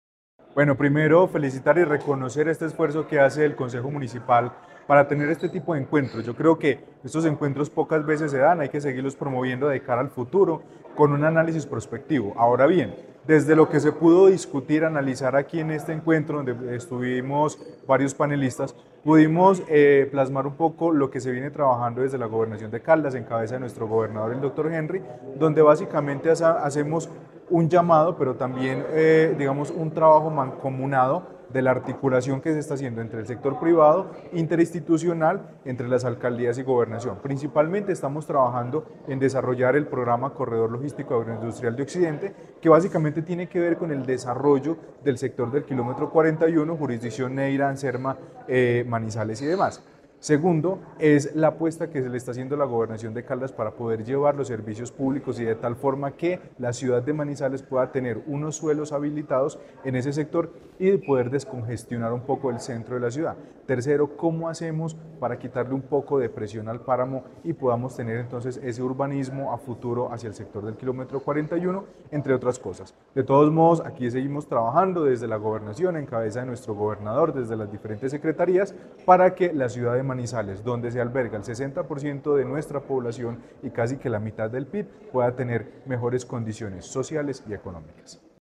Con el objetivo de promover una visión estratégica y prospectiva de Manizales hacia el año 2049, se llevó a cabo el “Foro Bicentenario», un espacio de diálogo abierto para construir colectivamente políticas públicas, proyectos estratégicos y planes de desarrollo sostenible que consoliden a la ciudad como un referente de innovación, competitividad y bienestar en Colombia.
Carlos Anderson García, secretario de Planeación de Caldas